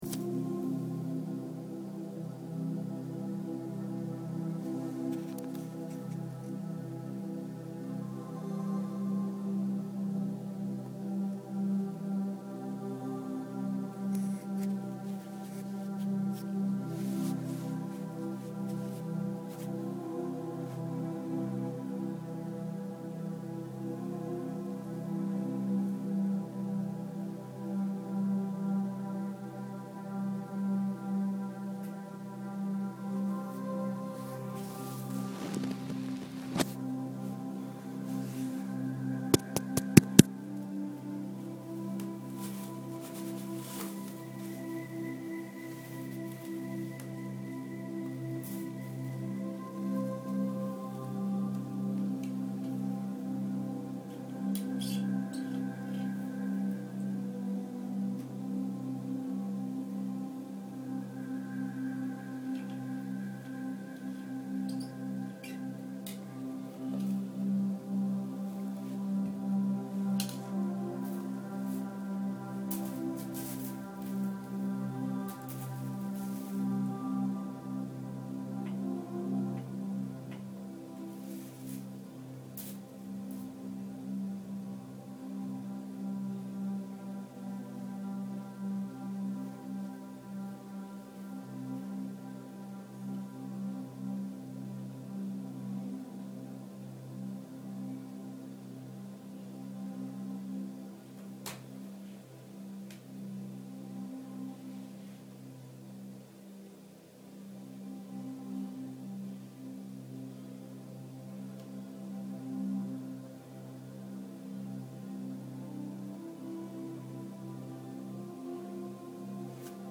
Channelled Message From LuSTARA Guides 11/28/12
There is a 2.5 min transmission of LuSTARA Energy at the beginning, then speaking begins.